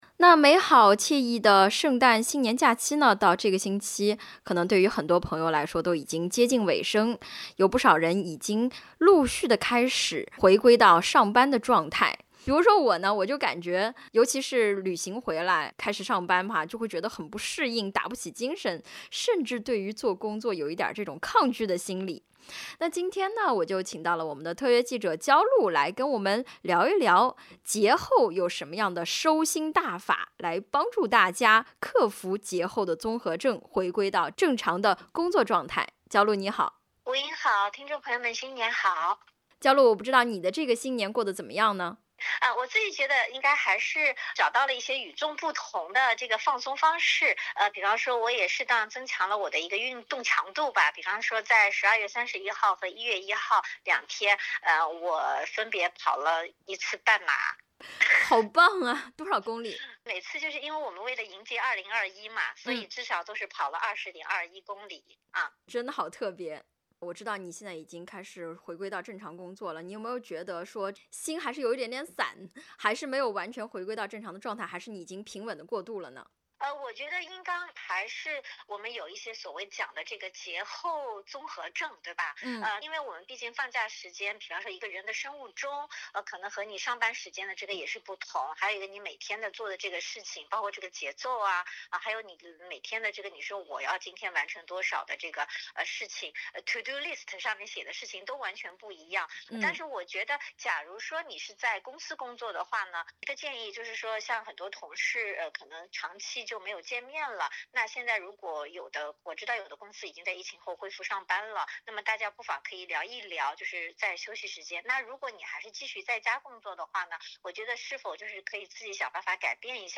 点击封面图片收听音频采访。